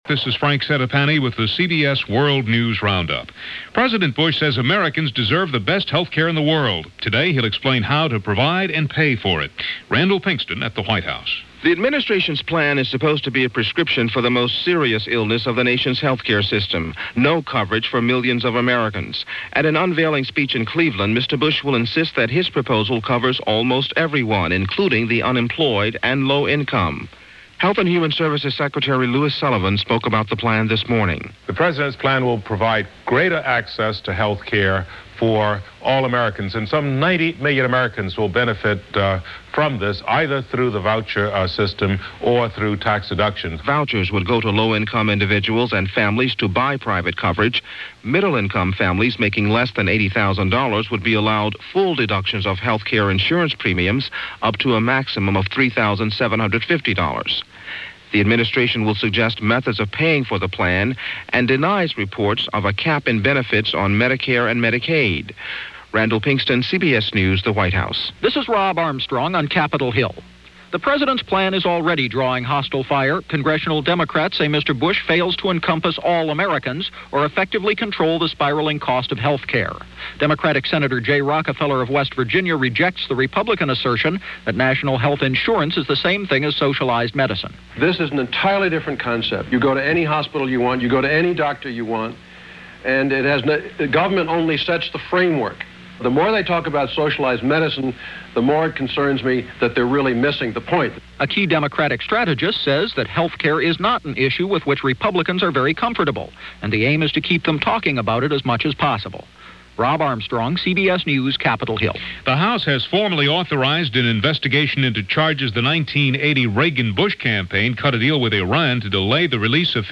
– CBS World News Roundup – February 6, 1992 – Gordon Skene Sound Collection –